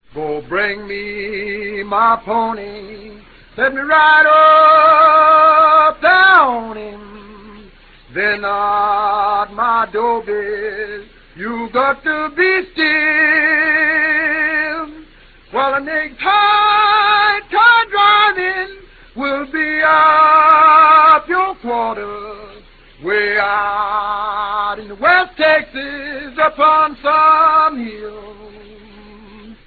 Balladeers and Songsters of the Texas Frontier
Well, rather a lot, actually.  29 tracks of field recordings made by the Lomaxes (primarily John) in East Texas between 1933 and 1940 and covering an extraordinary range of black folk musics that fed into and out of the popular musical styles of the day.